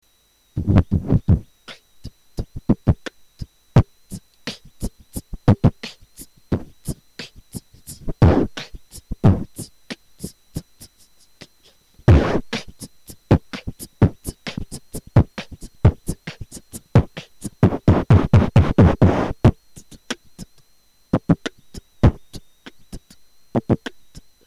качество записи конечно ужас... а сколько занимаешься?